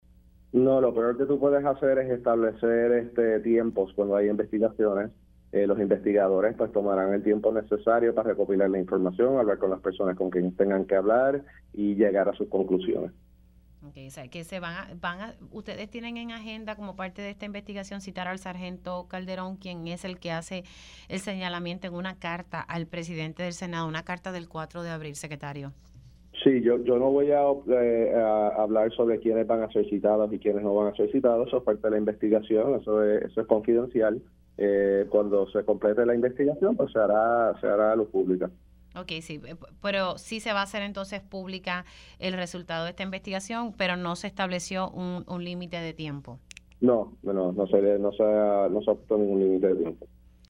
El secretario del Departamento de Seguridad Pública (DSP), General Arturo Garffer confirmó en Pega’os en la Mañana que le delegaron la investigación de la escolta de la secretaria de Justicia, Janet Parra al Negociado de la Policía de Puerto Rico (NPPR).